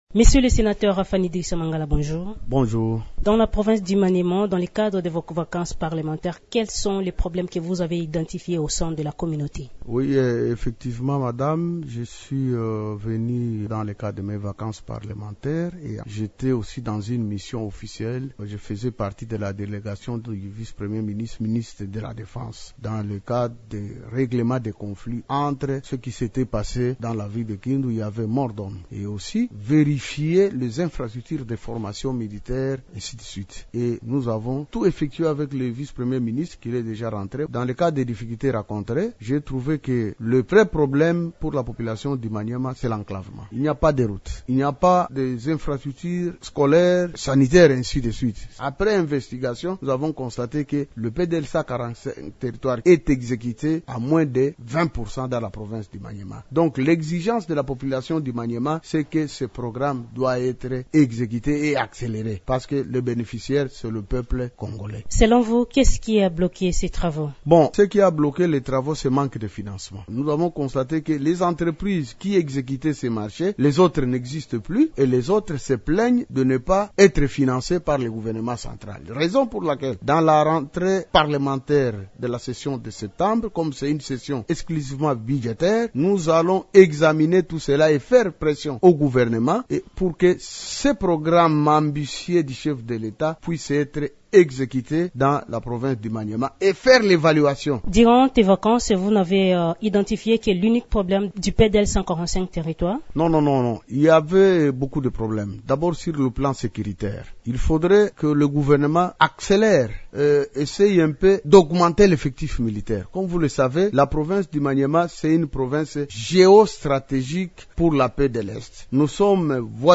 « Nous devons poursuivre ce programme ambitieux. Il est essentiel pour désenclaver notre province et améliorer les conditions de vie de nos populations », a plaidé le sénateur dans une interview à Radio Okapi.